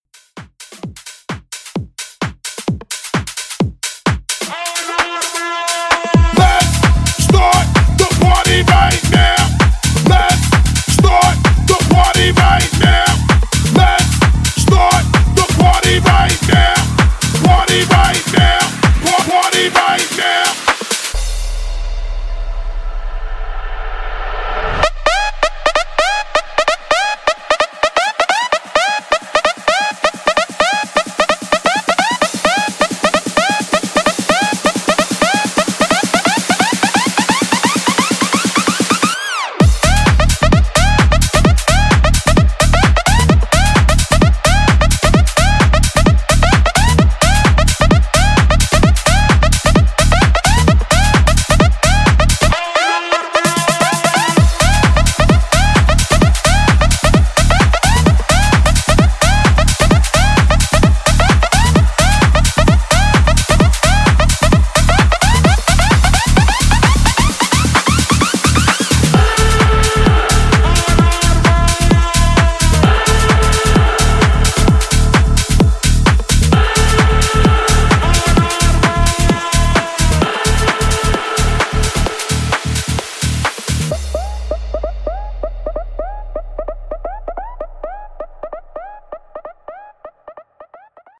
BPM: 130 Time